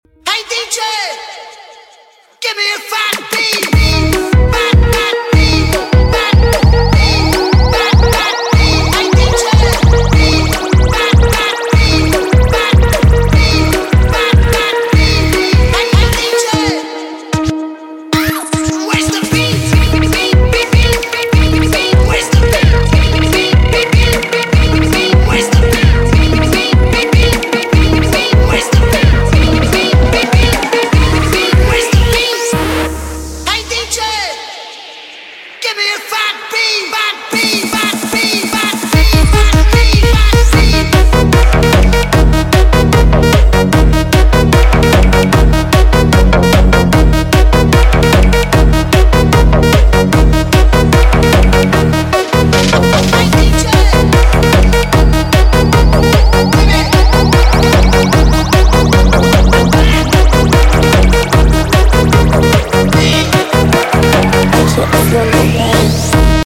Fast Beat